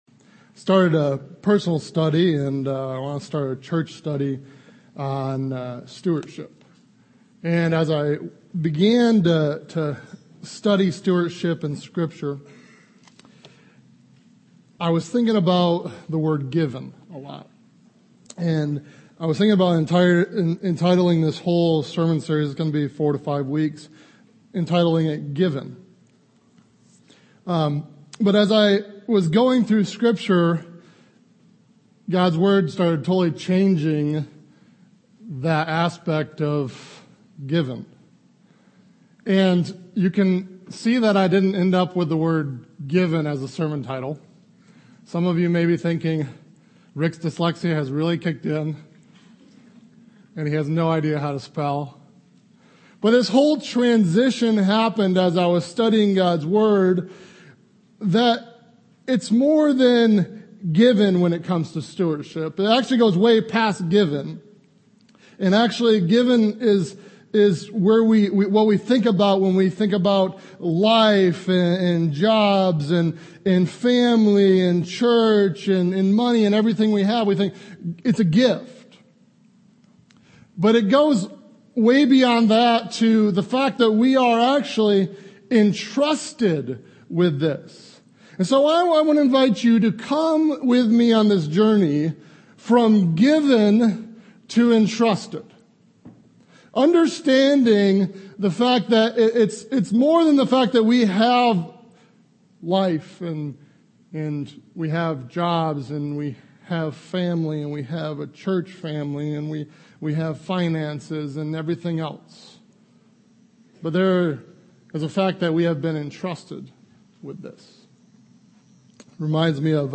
Rossville Bible Fellowship Sermons